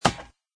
woodmetal3.mp3